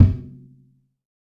TC3Kick9.wav